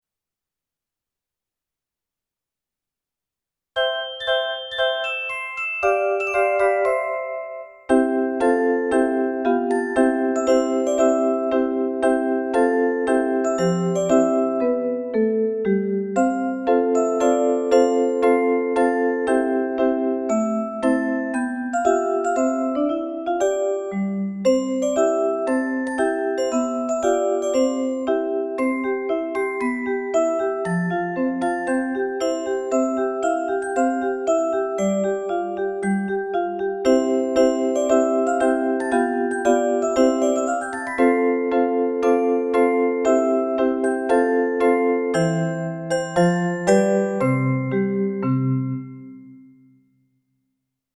応援歌
子供たちが歌っています。